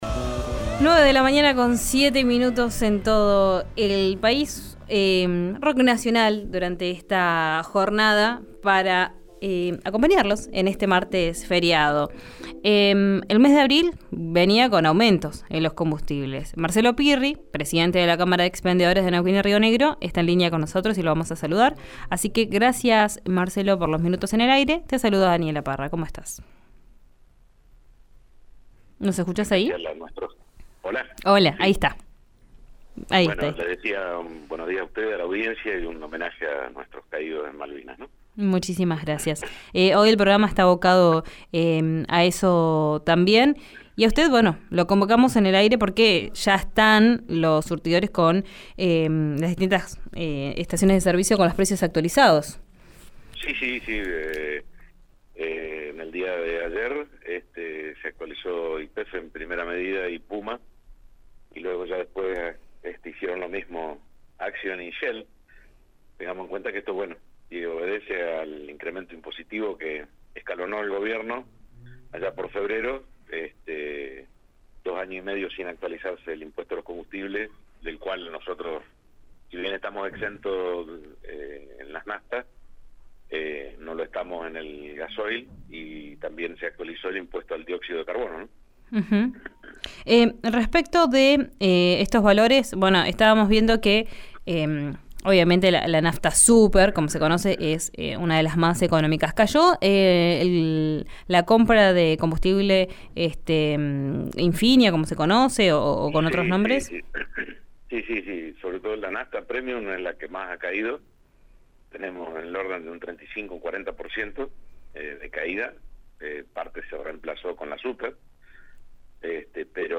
También se actualizó el impuesto al dióxido de carbono», señaló en diálogo con RÍO NEGRO RADIO.